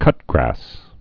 (kŭtgrăs)